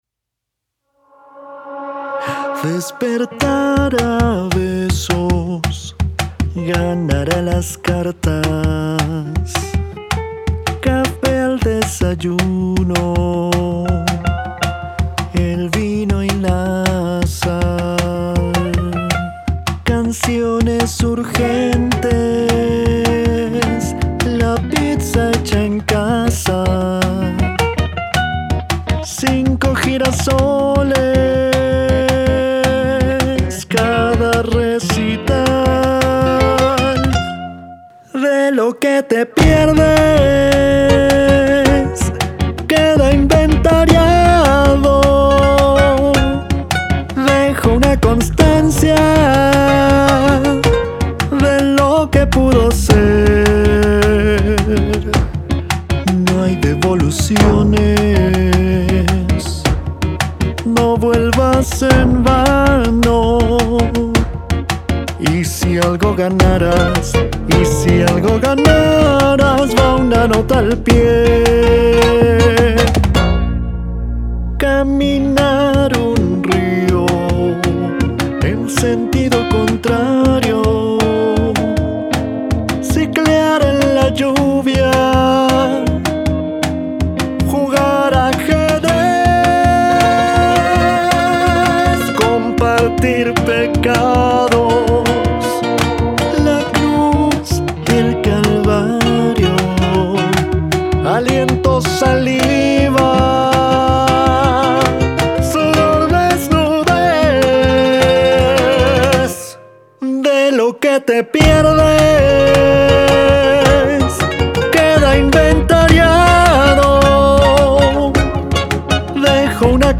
una canción de fusión latina